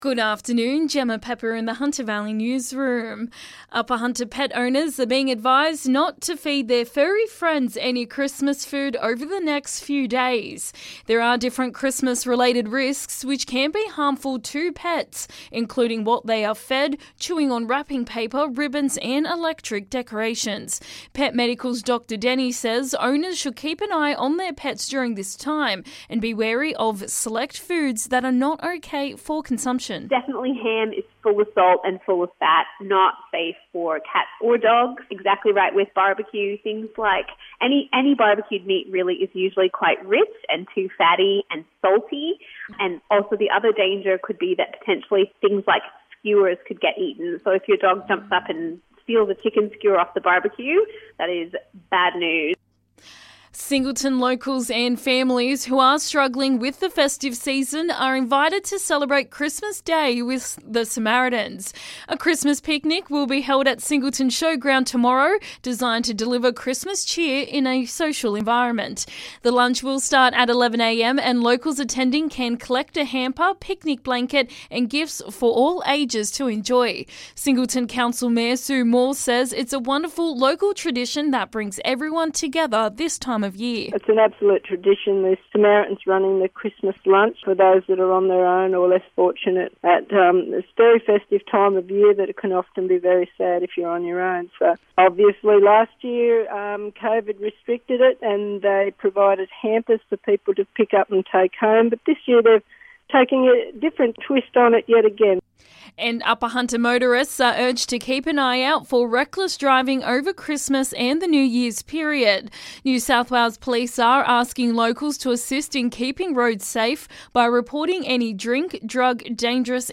LISTEN: Hunter Valley Local News Headlines 24/12/2021